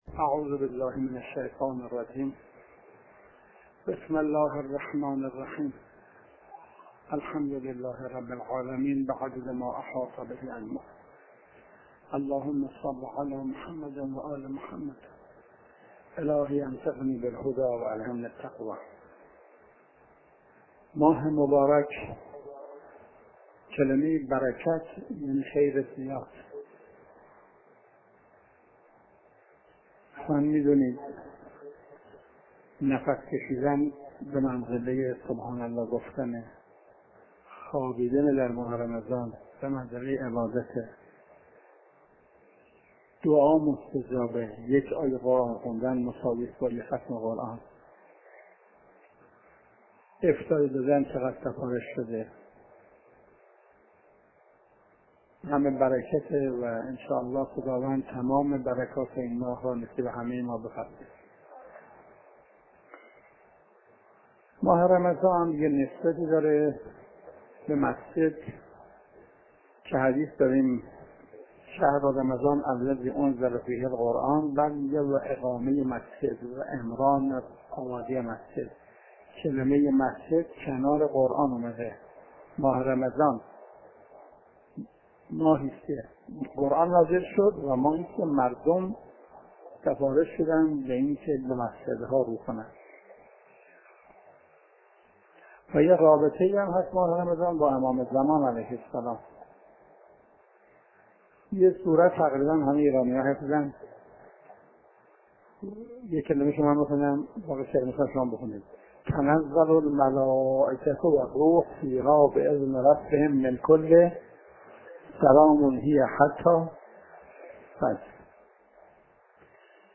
حجم: 5.92 MB | زمان: 24:32 | مکان: حرم امام رضا (علیه السلام) | تاریخ: ۲۷ / اردیبهشت / ۱۳۹۷ش